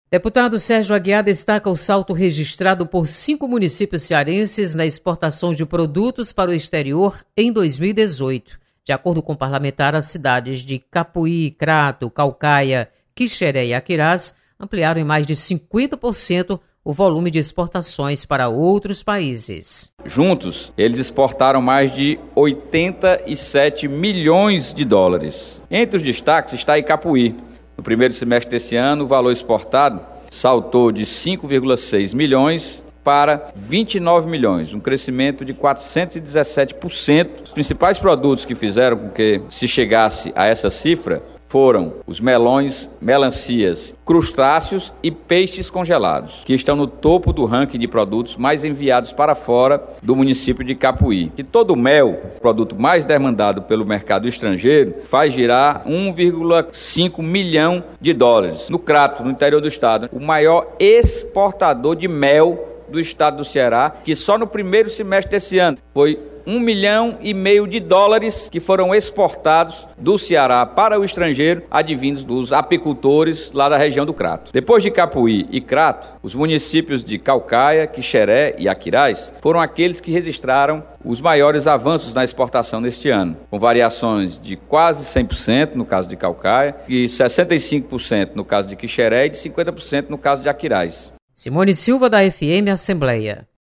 Deputado Sérgio Aguiar comemora crescimento da exportação. Repórter